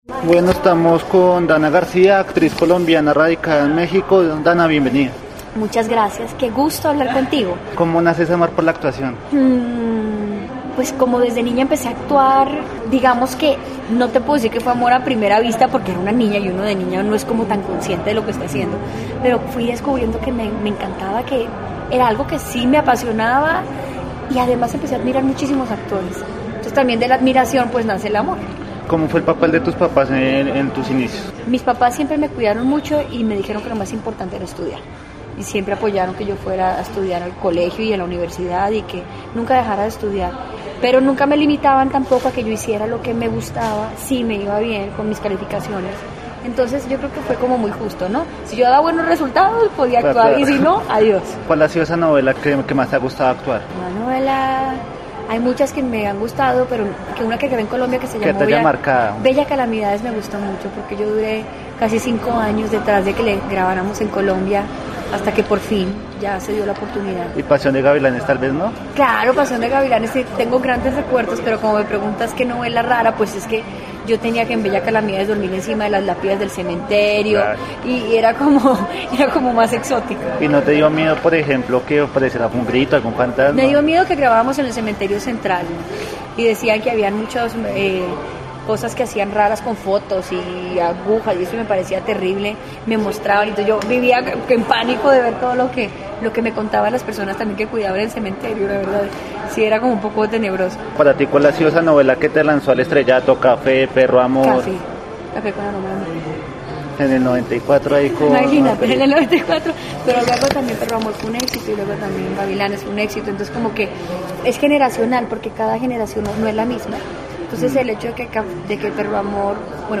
En UNIMINUTO Radio estuvo la actriz colombiana Danna García quien vive en México desde hace varios años y que se ha convertido en una celebridad por los protagónicos que ha realizado en ese país.